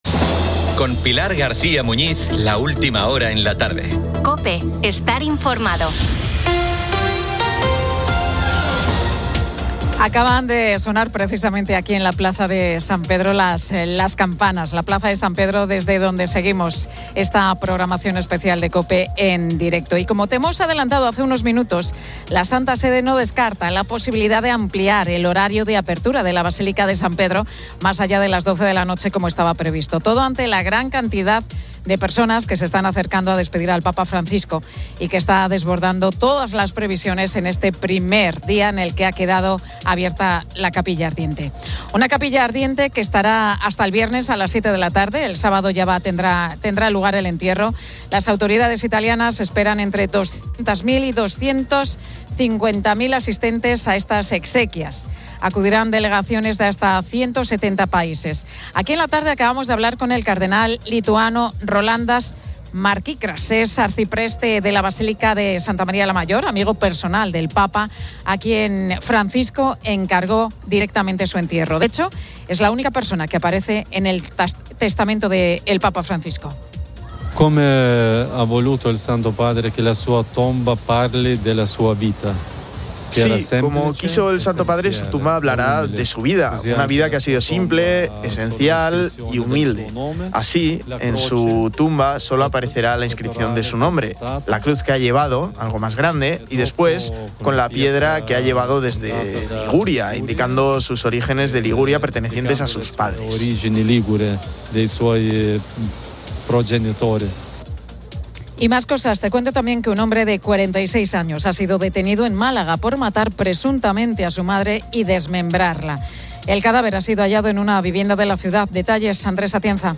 La Tarde 17:00H | 23 ABR 2025 | La Tarde Pilar García Muñiz y el equipo de La Tarde desde Roma en el primer día de capilla ardiente del Papa Francisco. Recordamos el humor del Pontífice con la actriz Cristina Castaño, descubrimos las cofradías que procesionarán en Roma y conocemos Mairena del Alcor (Sevilla).